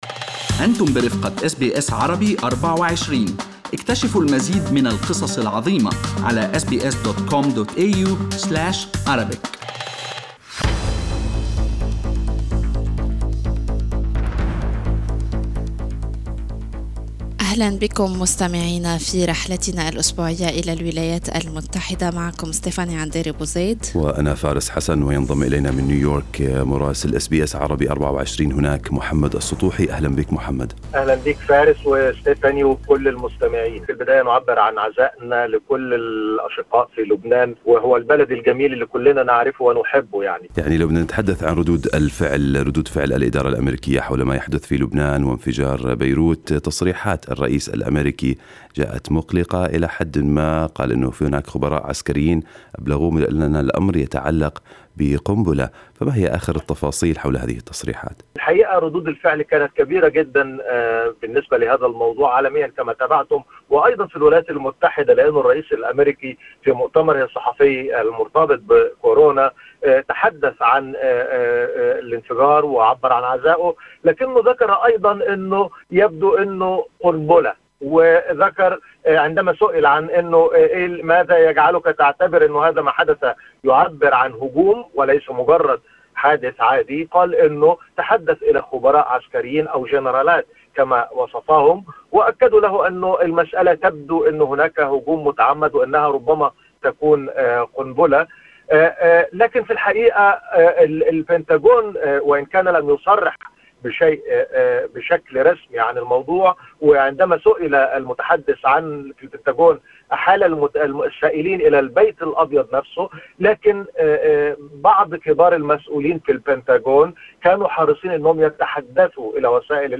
من مراسلينا: أخبار الولايات المتحدة الأمريكية في أسبوع 6/8/2020